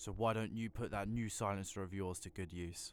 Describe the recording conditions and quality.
Added all voice lines in folders into the game folder